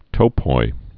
(tōpoi)